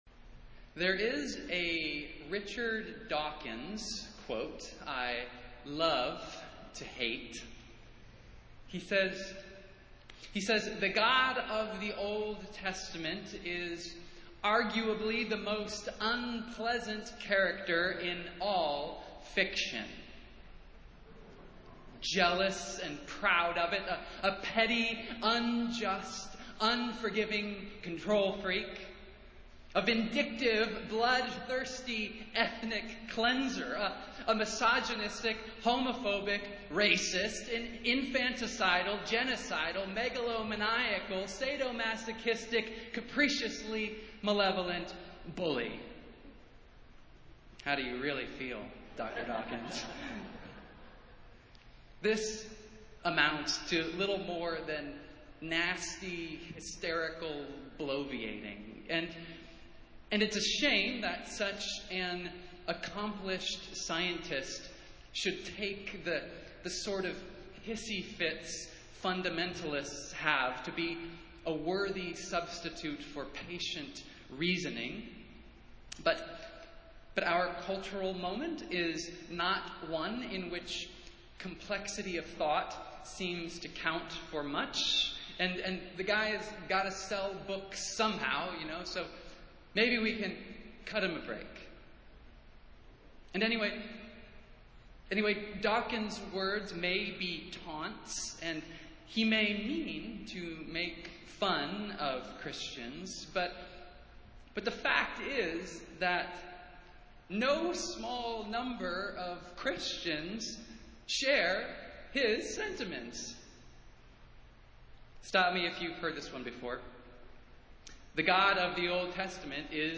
Festival Worship - Fourteenth Sunday after Pentecost